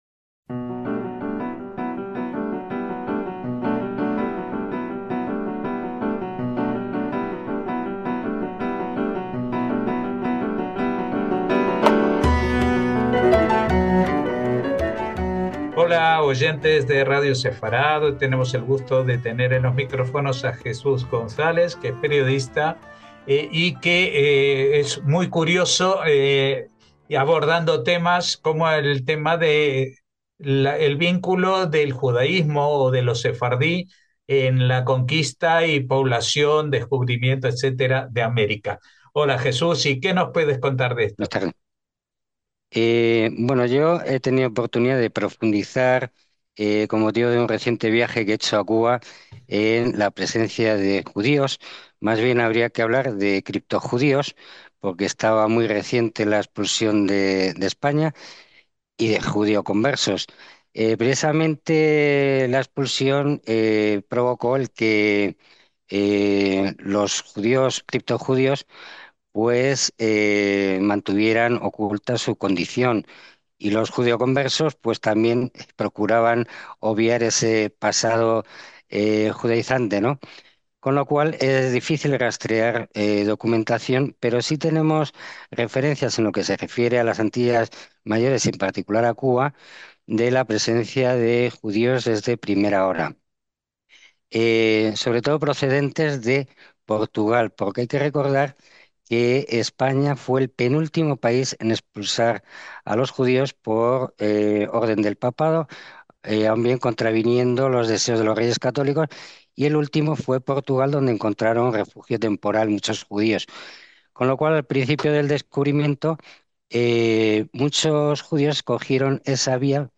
Y la historia no acaba entonces sino que prosiguió en los siguientes siglos, como nos relata detalladamente nuestro entrevistado.